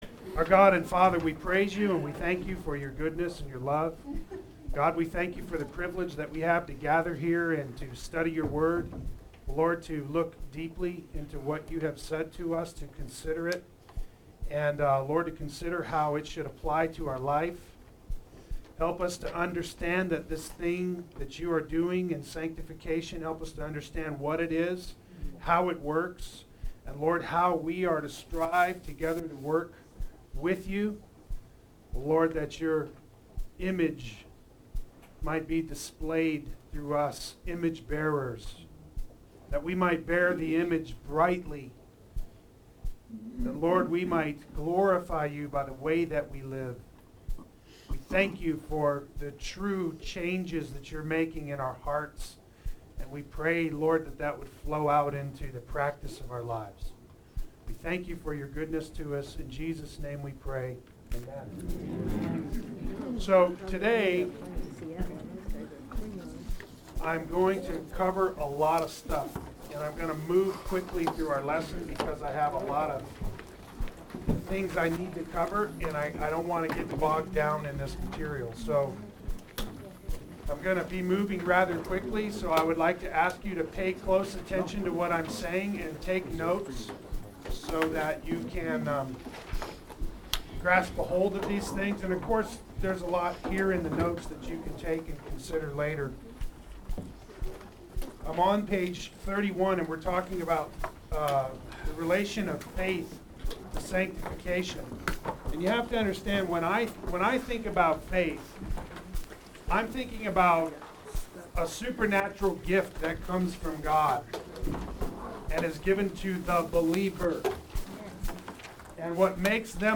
Sanctification and Faith Adult Sunday School